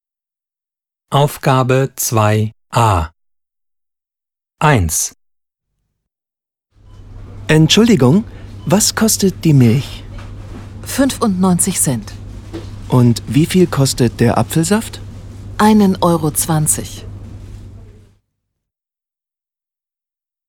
Dialog 1: